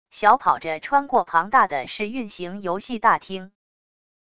小跑着穿过庞大的试运行游戏大厅wav音频